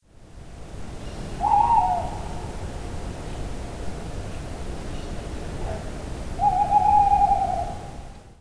دانلود صدای جغد درجنگل برای کودکان از ساعد نیوز با لینک مستقیم و کیفیت بالا
جلوه های صوتی
برچسب: دانلود آهنگ های افکت صوتی انسان و موجودات زنده